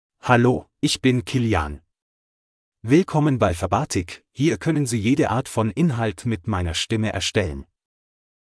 MaleGerman (Germany)
Killian is a male AI voice for German (Germany).
Voice sample
Listen to Killian's male German voice.
Killian delivers clear pronunciation with authentic Germany German intonation, making your content sound professionally produced.